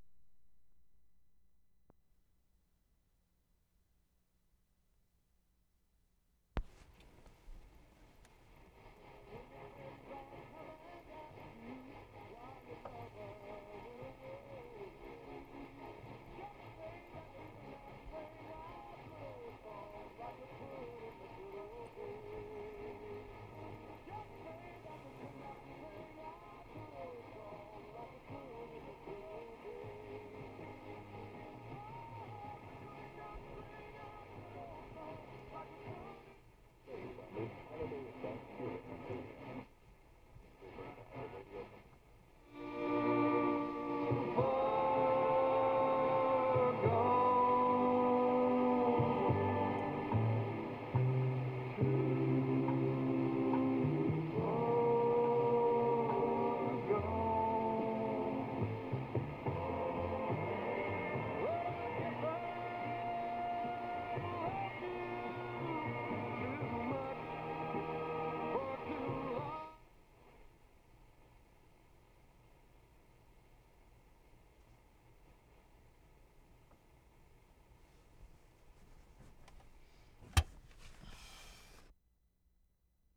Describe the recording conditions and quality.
BANFF, ALBERTA Nov. 14, 1973